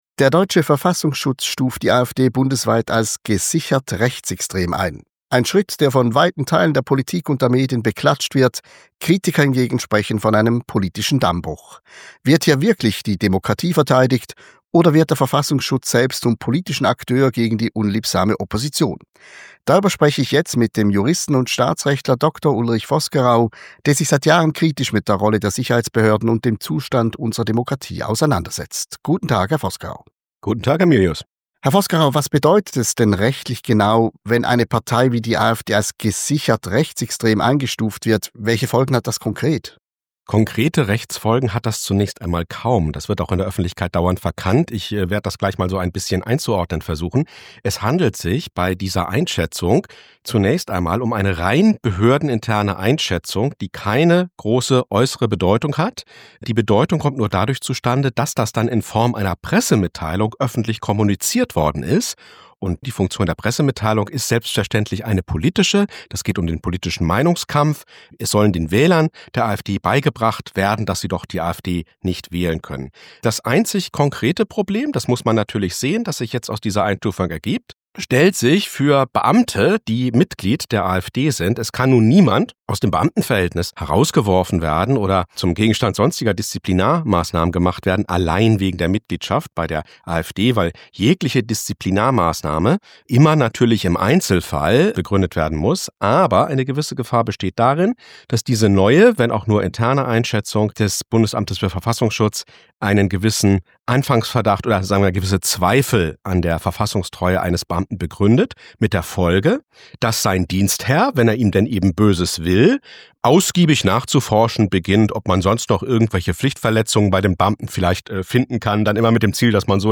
Zunächst galt sie als Verdachtsfall, nun soll die AfD laut Verfassungsschutz gesichert rechtsextrem sein. Was davon zu halten ist und wie die Folgen aussehen, sagt der Jurist und Staatsrechtler